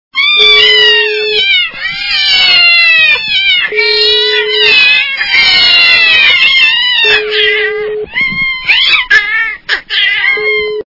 Вопли котов - Крики котов Звук Звуки Воплі котів - Шалені крики диких котів
» Звуки » Природа животные » Вопли котов - Крики котов
При прослушивании Вопли котов - Крики котов качество понижено и присутствуют гудки.